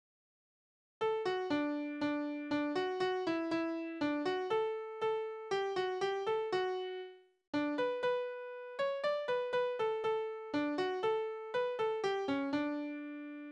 Balladen: Das Mädchen mit den 2 Liebhabern
Tonart: D-Dur
Taktart: 3/4
Tonumfang: Oktave
Besetzung: vokal
Anmerkung: Vortragsbezeichnung: Ruhig